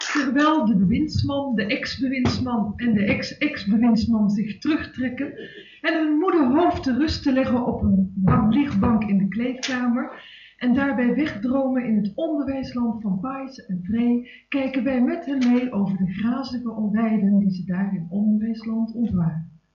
Opname tijdens een Try Out met een cassetterecorder, matige kwaliteit.
Voice over